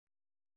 ♪ jhāḍigedaṛu